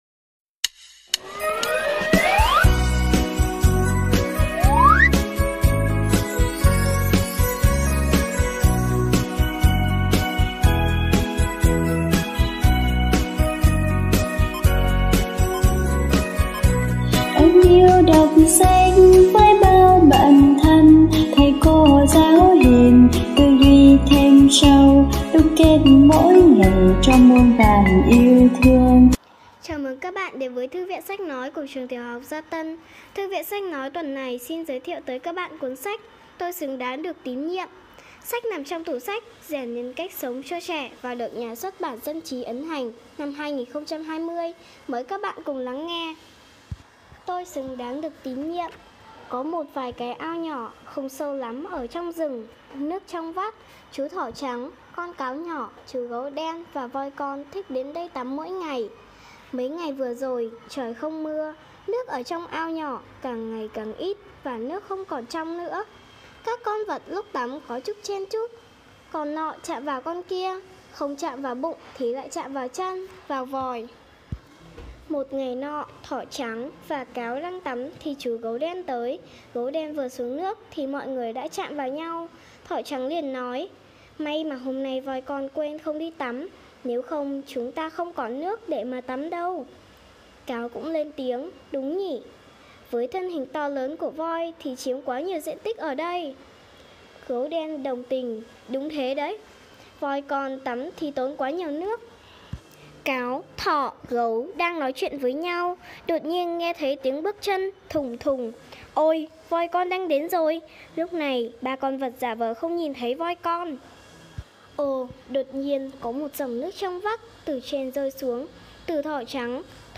Sách nói | Thư viện sách nói tuần 8: Tôi xứng đáng được tín nhiệm